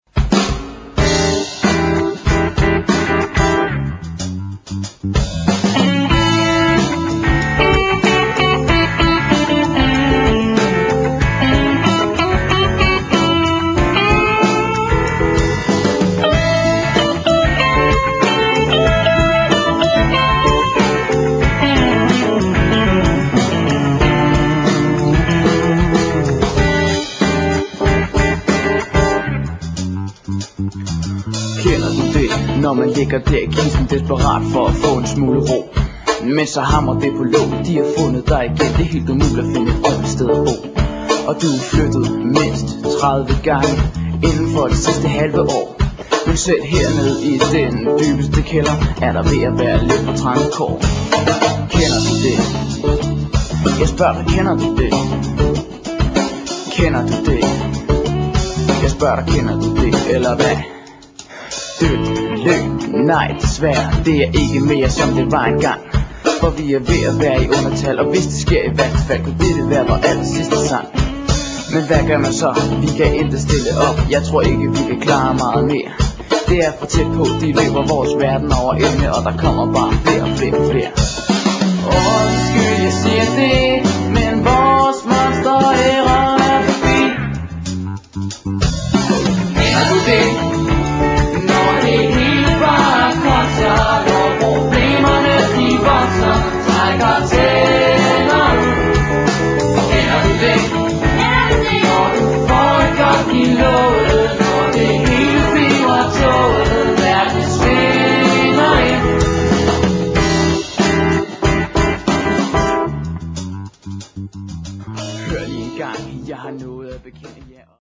Der er både pop, rock og rumba.